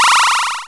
Player Gain Health.wav